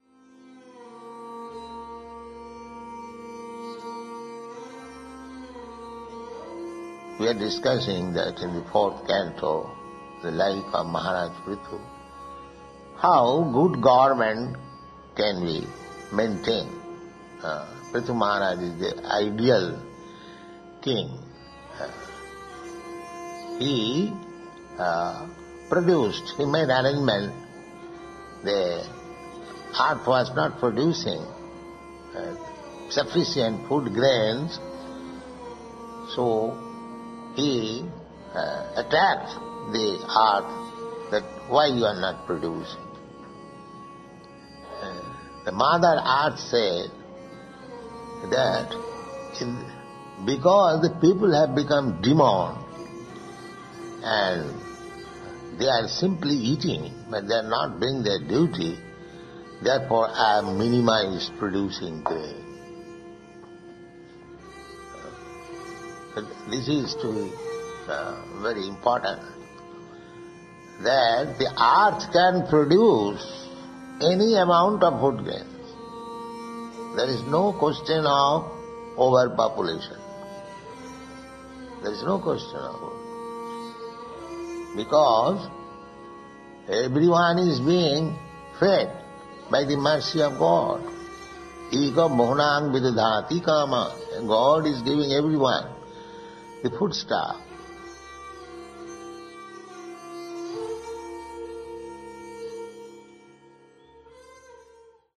(720919 – Lecture SB 01.03.14 – Los Angeles)